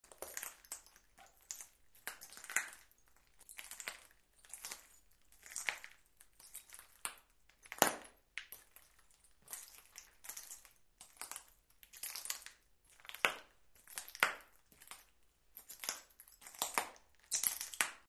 Звук шагов по разбитому сердцу